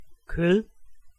Ääntäminen
France (Paris): IPA: [la ʃɛʁ]